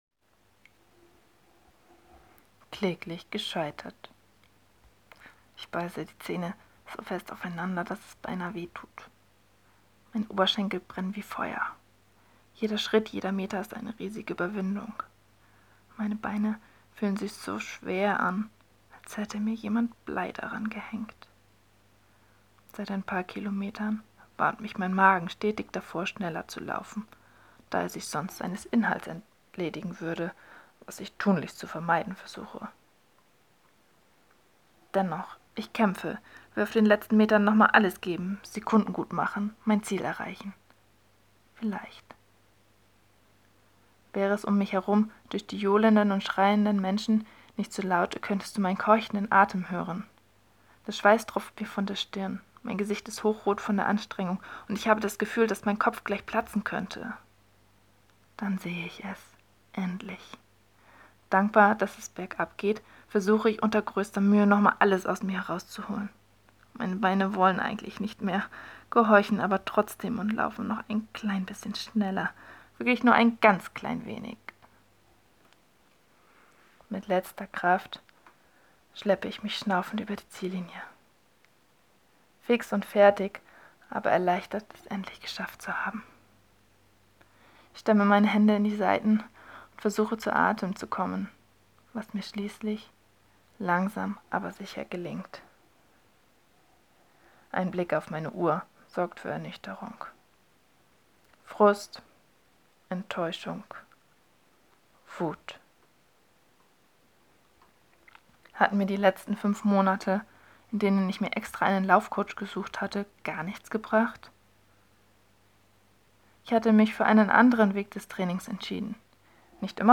Gerne lese ich dir alles vor